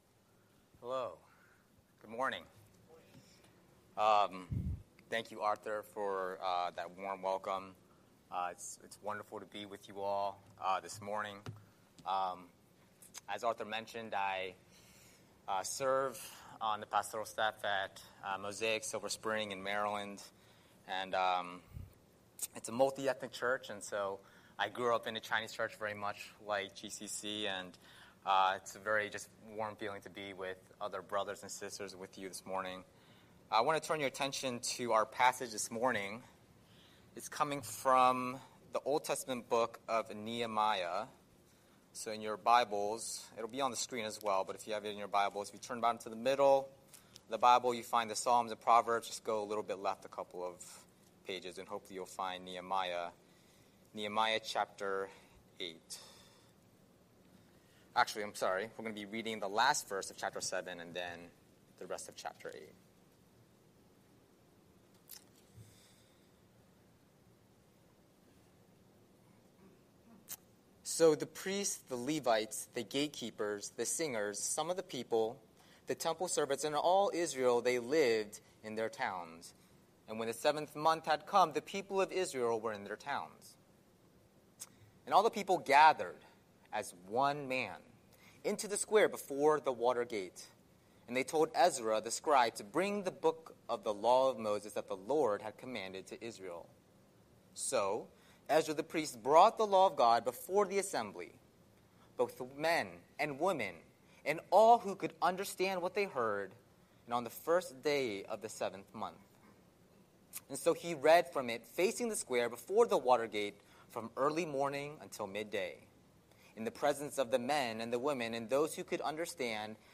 Scripture: Nehemiah 7:73–8:18 Series: Sunday Sermon